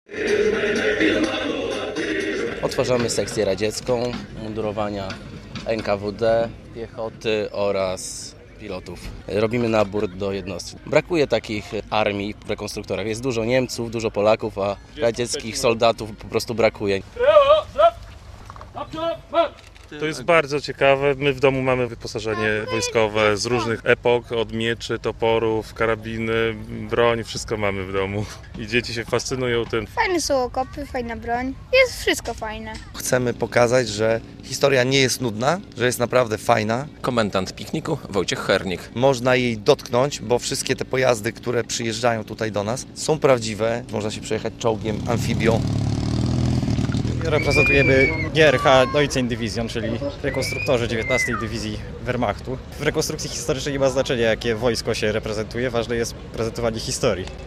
Piknik militarny w Ogrodniczkach - relacja